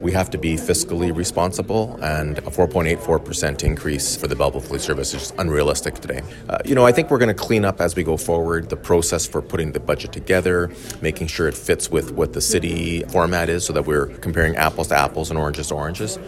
Mayor Mitch Panciuk tells Quinte News he understands the needs and pressure faced by the police service and other departments to increase their budgets, but the original ask was just too high.